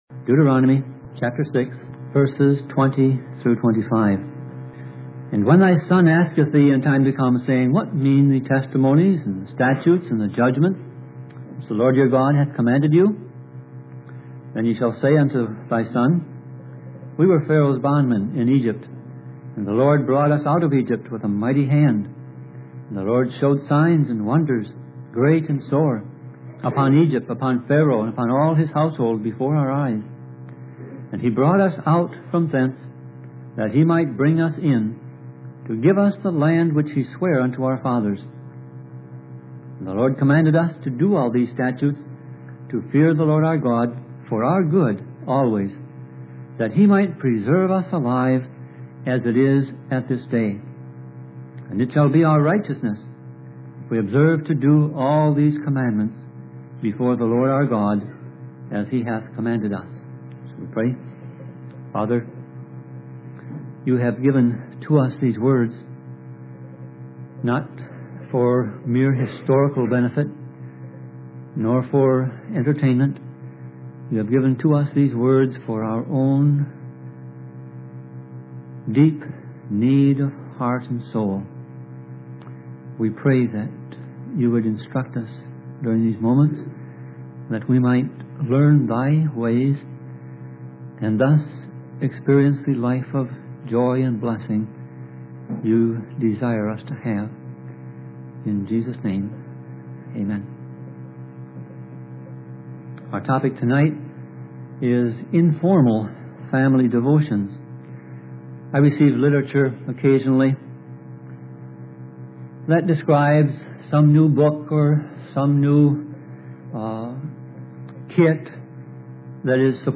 Series: Sermon Audio Passage: Deuteronomy 6:20-25 Service Type